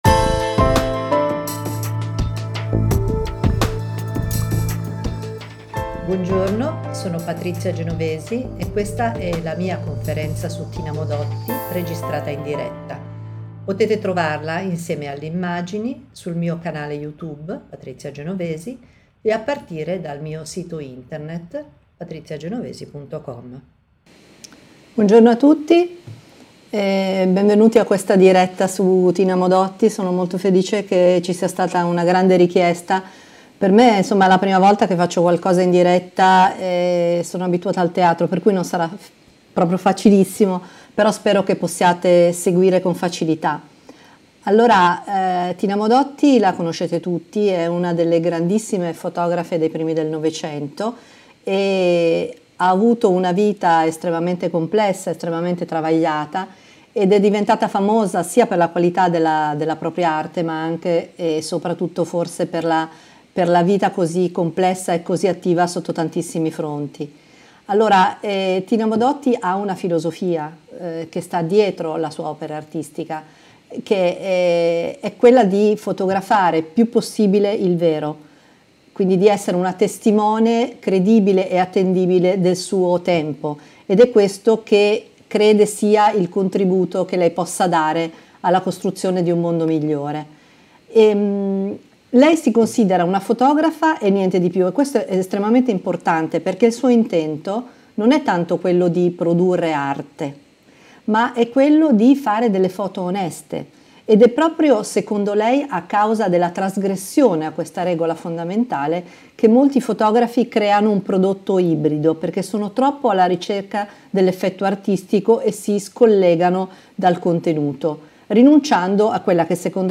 Vita, stile, opera di Tina Modotti. Podcast registrato durante la diretta della Masterclass.